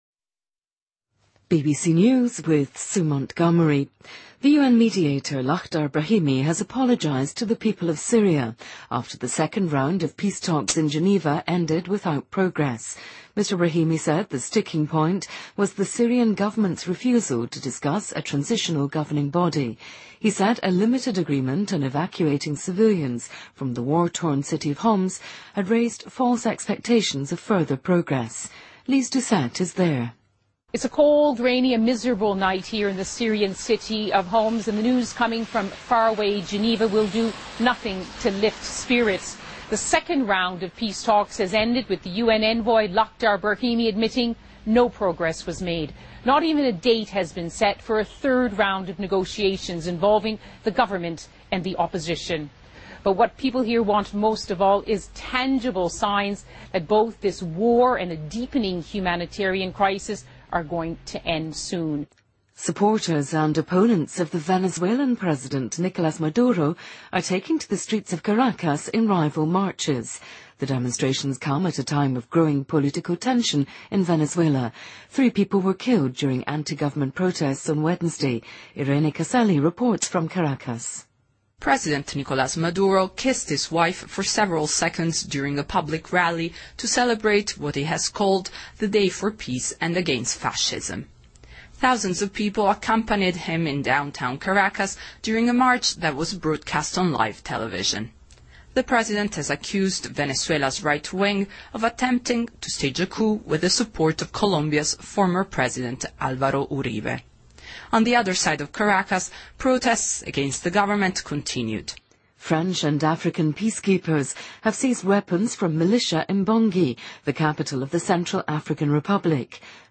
BBC news,2014-02-16